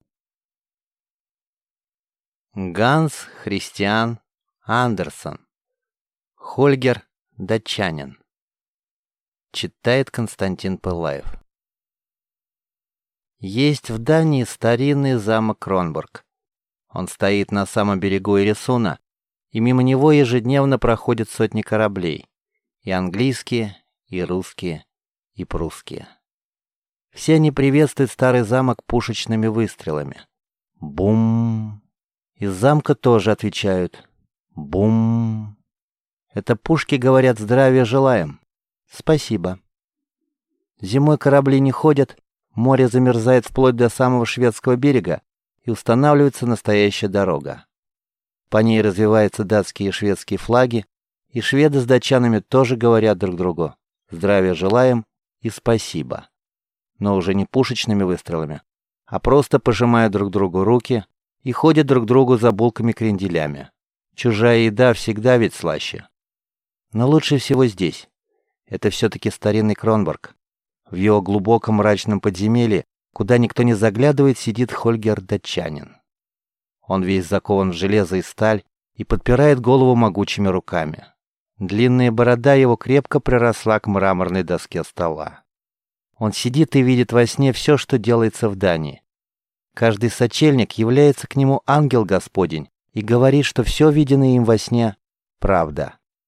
Аудиокнига Хольгер Датчанин | Библиотека аудиокниг